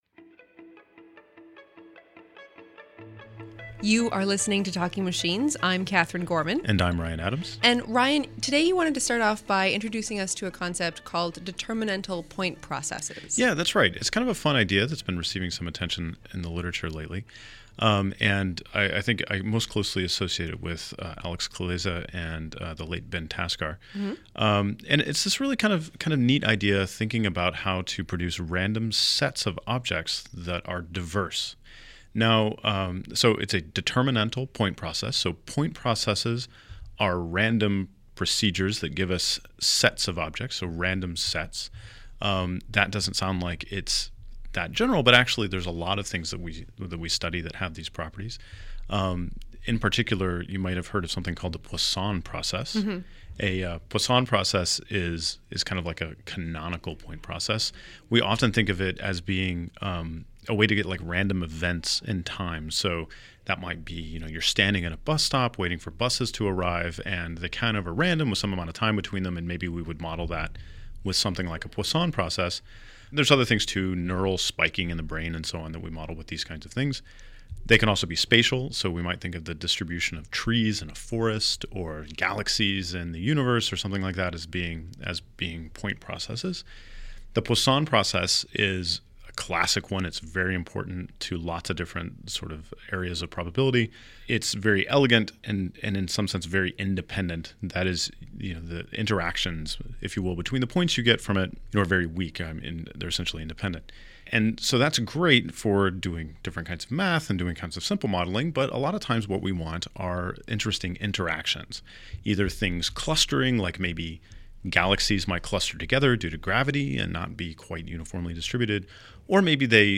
We hear the second part of our conversation with with Geoffrey Hinton (Google and University of Toronto), Yoshua Bengio (University of Montreal) and Yann LeCun (Facebook and NYU).